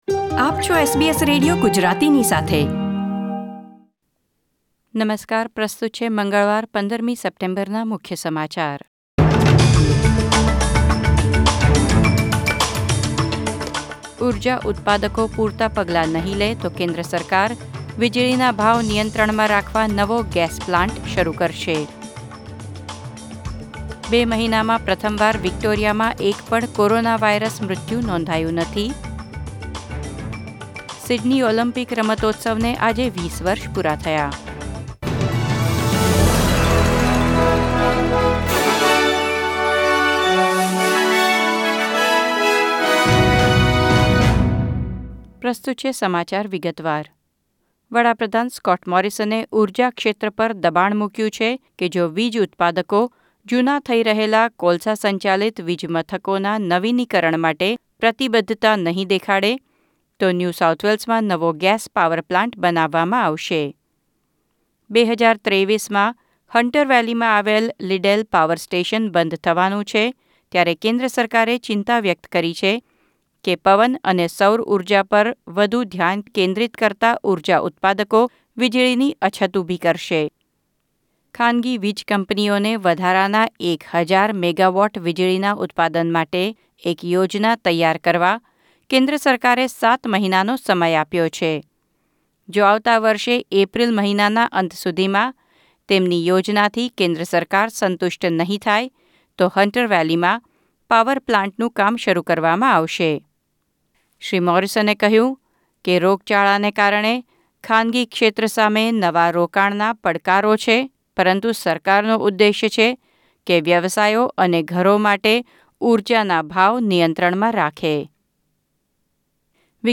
SBS Gujarati News Bulletin 15 September 2020